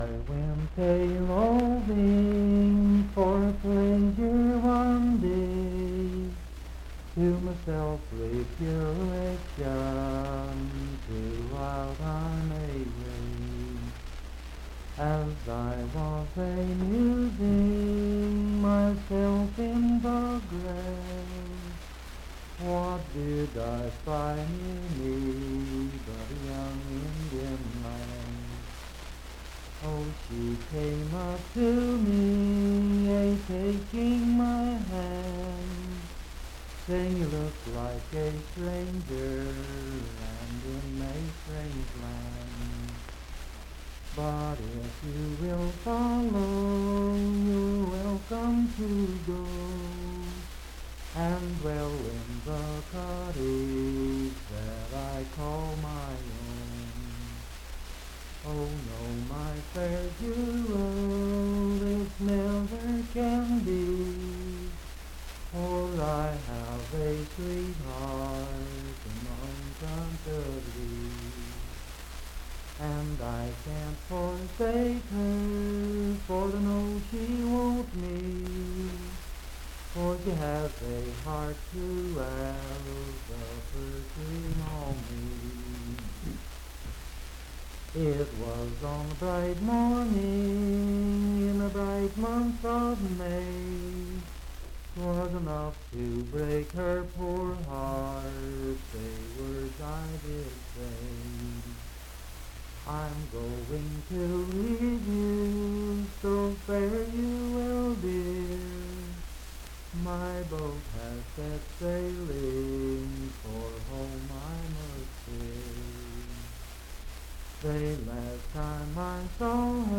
Unaccompanied vocal music
Verse-refrain 7d(4).
Voice (sung)
Pocahontas County (W. Va.), Marlinton (W. Va.)